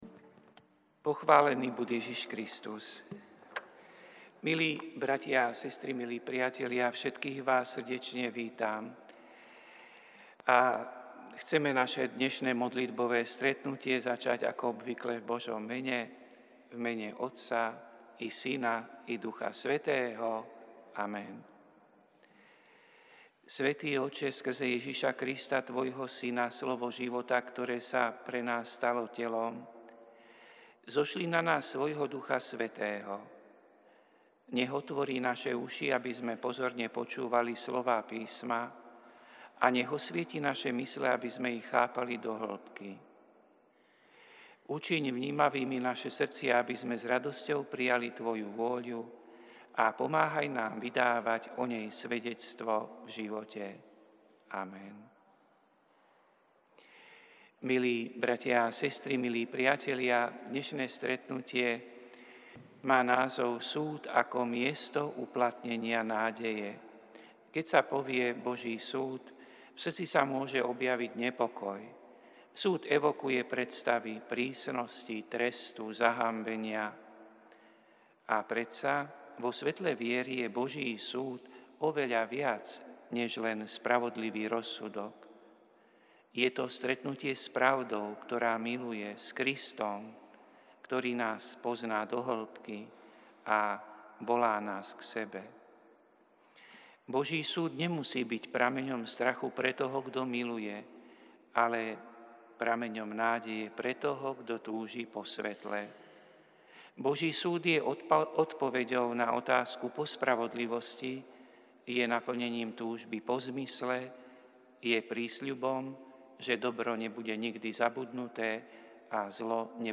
Prinášame plný text a audio záznam z Lectio divina, ktoré odznelo v Katedrále sv. Martina 4. júna 2025.